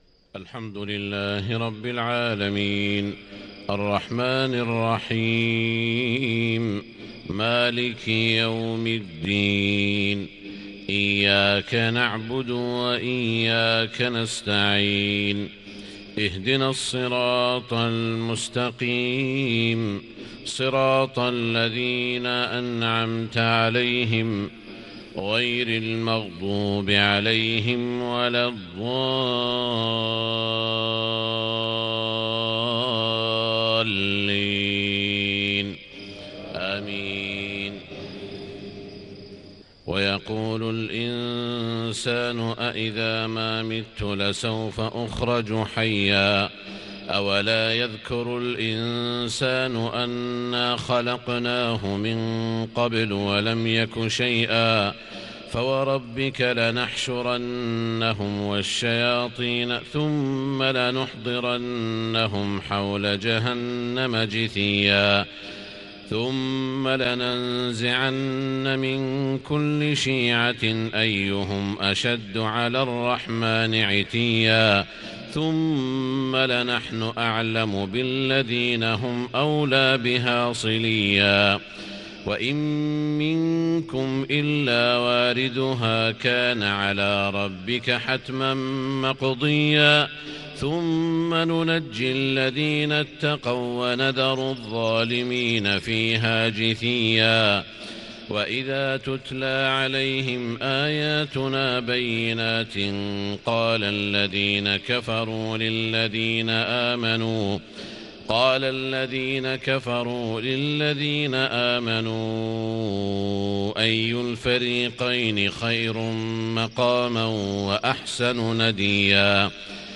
صلاة الفجر 1-5-1440هـ من سورة مريم | Fajr 7-1-2019 Prayar from Surah Maryam > 1440 🕋 > الفروض - تلاوات الحرمين